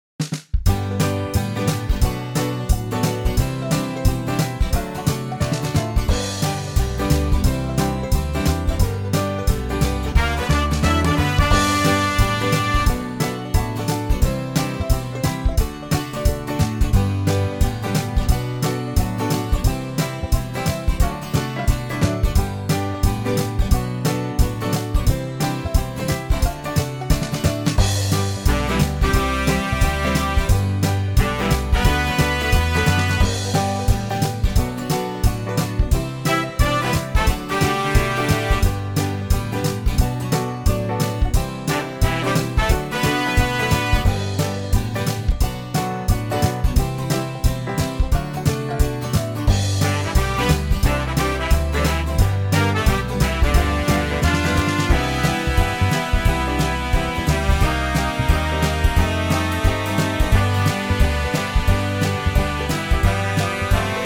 Unique Backing Tracks
HARMONICA AND SOLO REMOVED
MP3 NO SOLO DEMO:
key - G - vocal range - E to E
Suitable for typical male ranges.